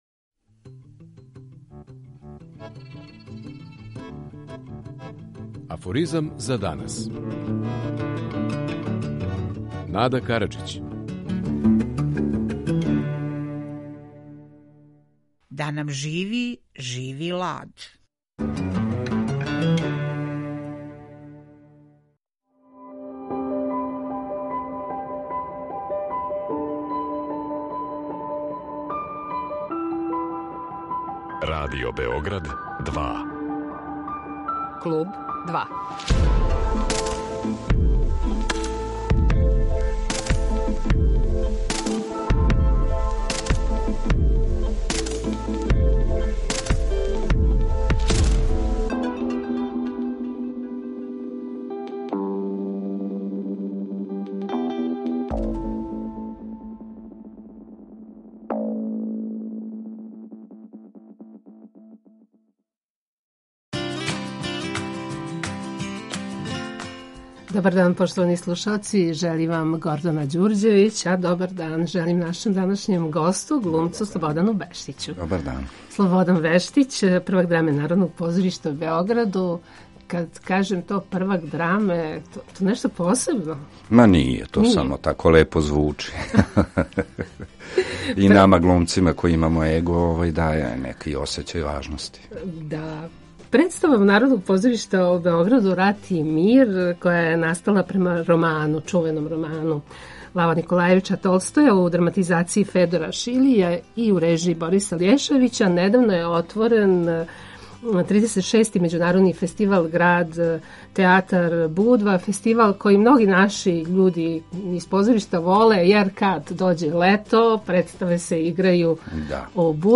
Гост Клуба 2 је глумац Слободан Бештић, првак Драме Народног позоришта у Београду.